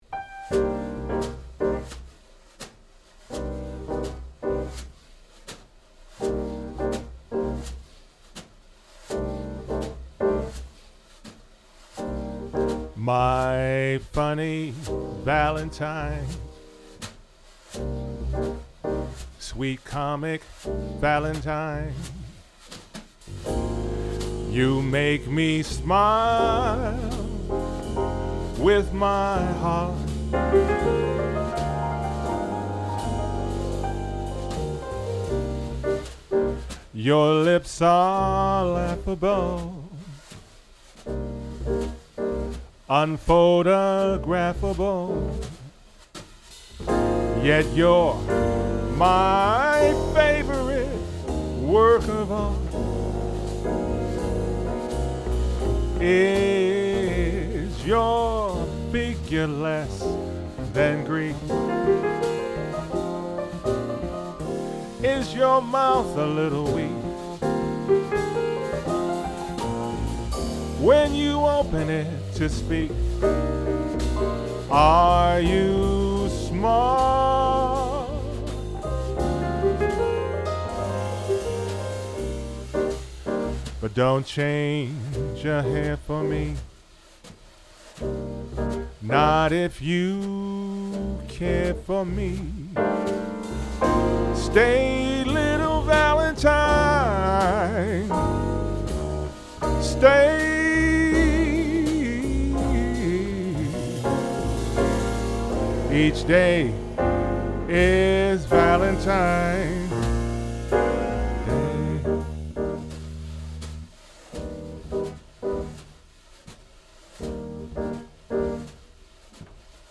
jazz standards